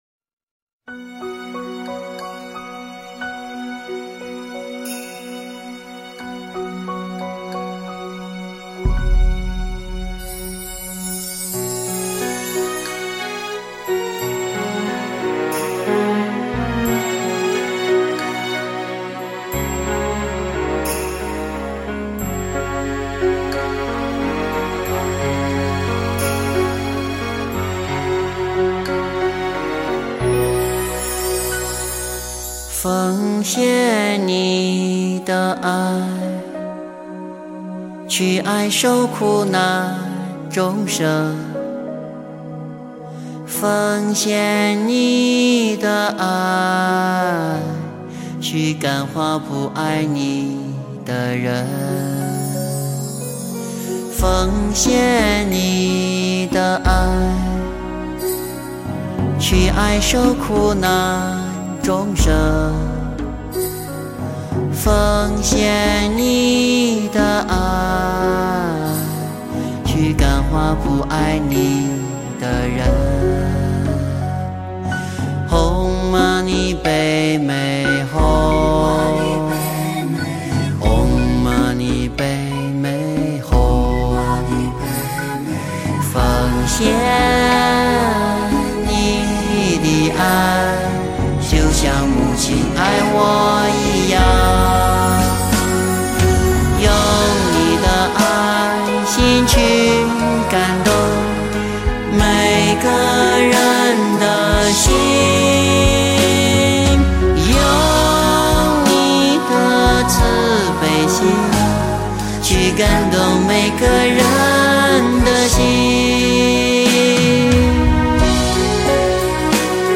佛音 凡歌 佛教音乐 返回列表 上一篇： 聆听(大悲咒